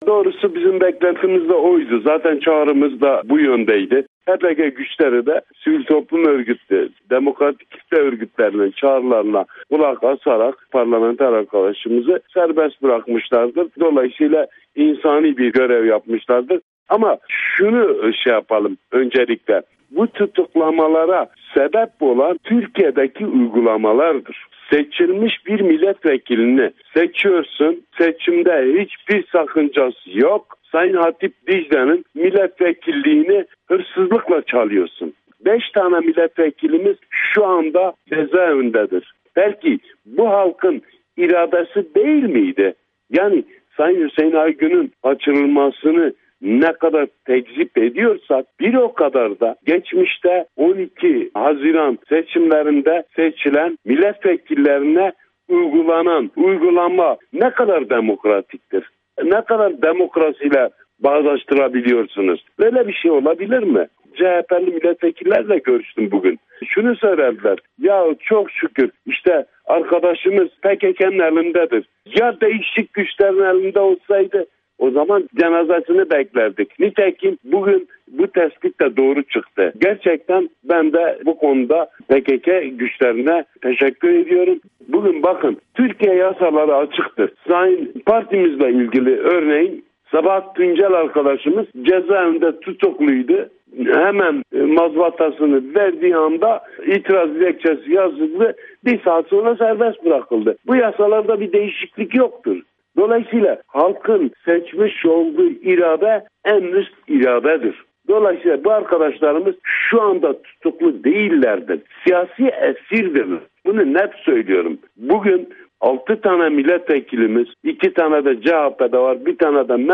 İbrahim Binici ile Söyleşi